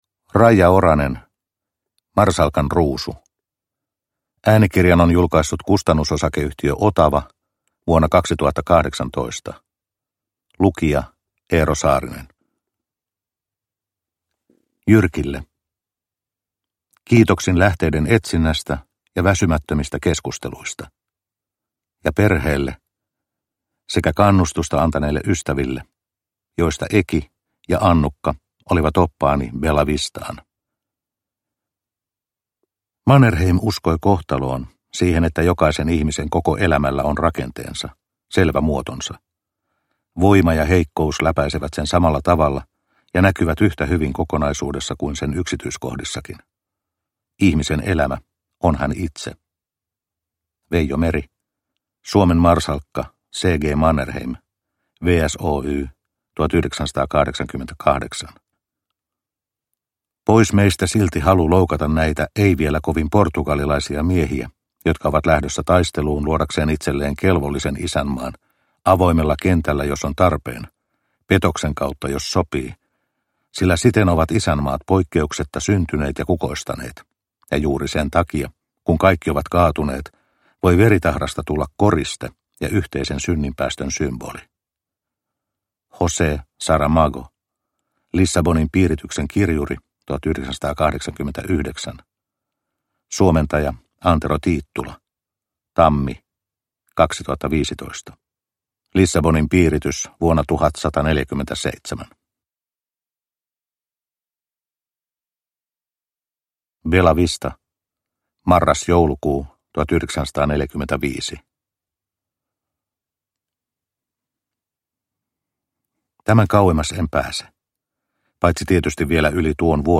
Marsalkan ruusu – Ljudbok – Laddas ner